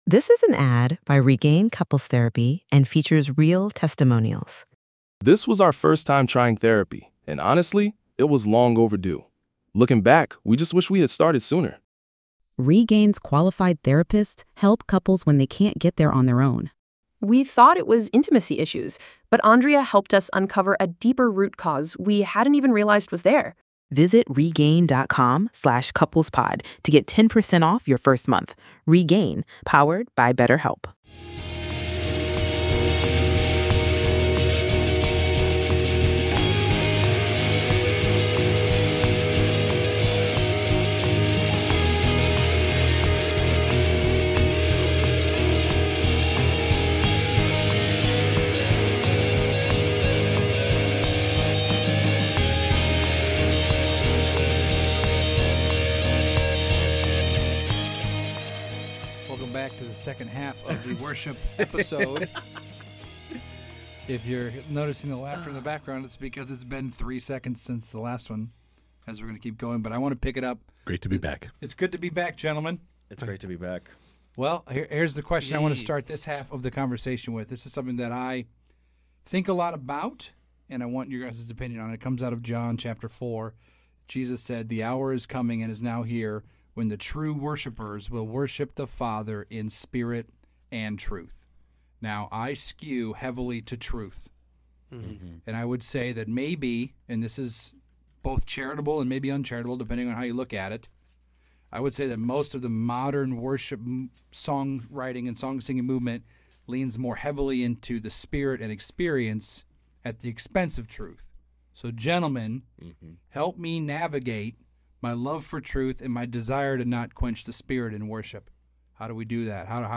Worship Interview Part 2